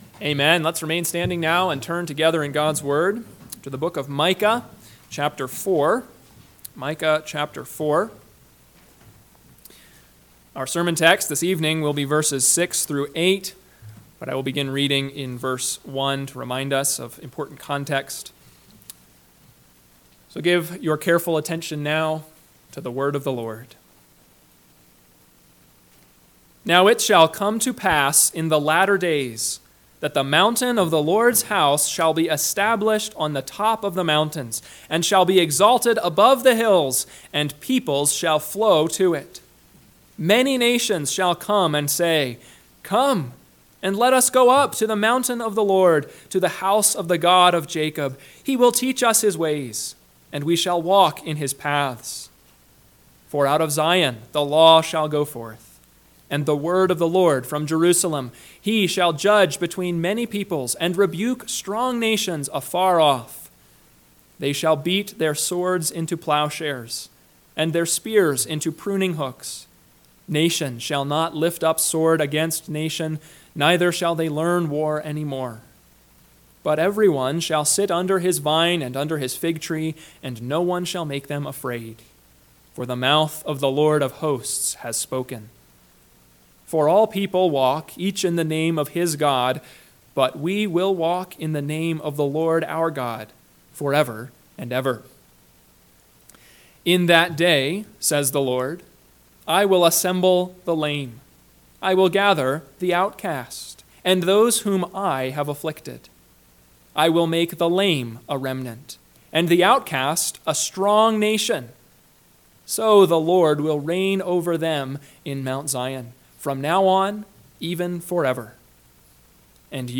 PM Sermon – 10/20/2024 – Micah 4:6-8 – Northwoods Sermons